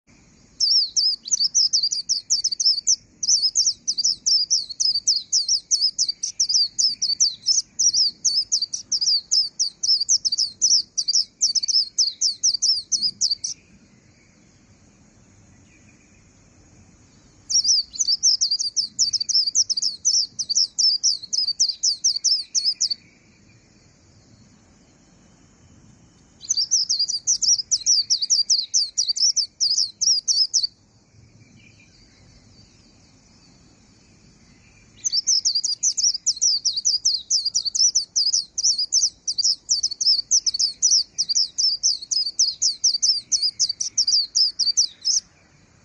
红胁绣眼鸟叫声